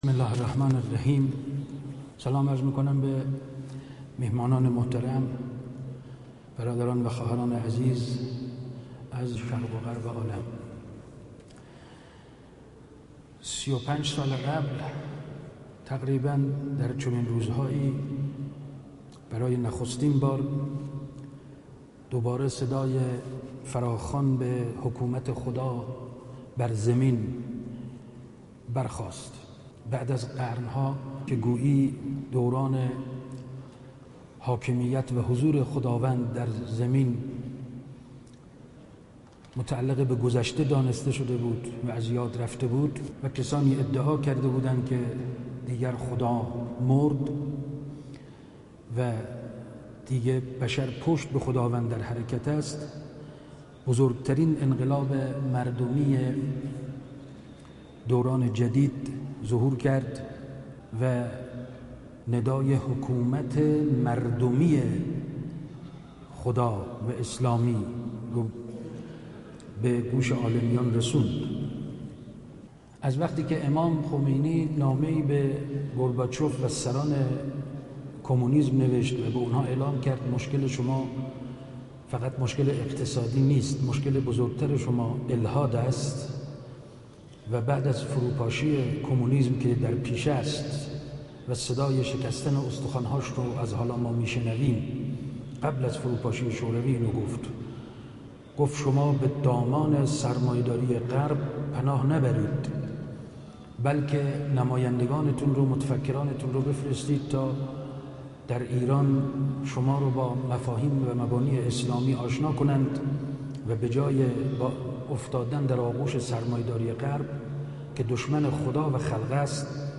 1 آخرین مطالب موسیقی سخنرانی دانلود سخنرانی استاد رحیم پور ازغدی:وجدان عمومی غرب، تشنه حضرت محمد (ص) است اما…